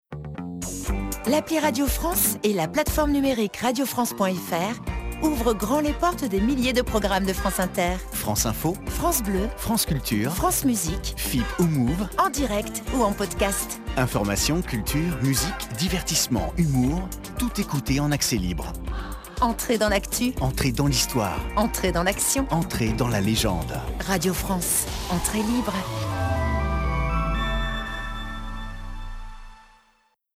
ado